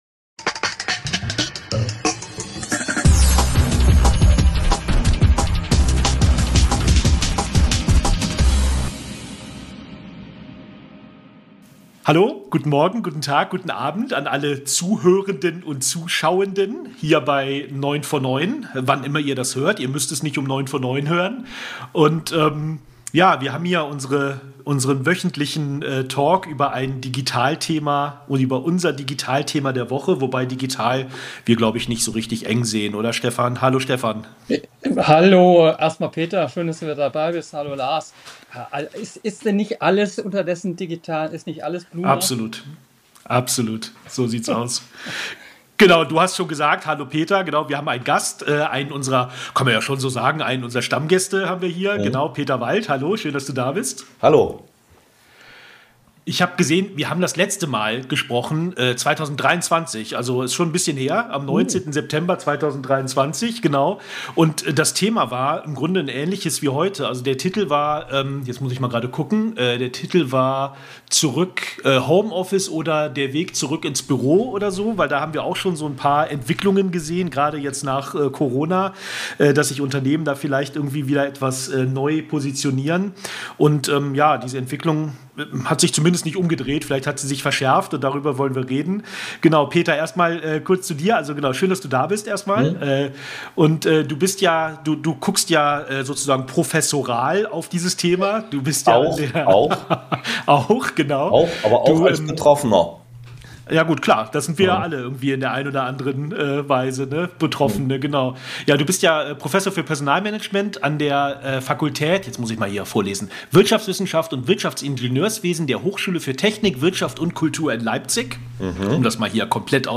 Folgt Deutschland hier einmal mehr dem Trend, den wir in Trumps und Musks USA sehen? Was steckt dahinter und worauf kommt es an, um eine für alle Beteiligten gute, motivierende und erfolgreiche Arbeitsumgebung zu schaffen, egal wo man sich befindet? Wir sprechen drüber.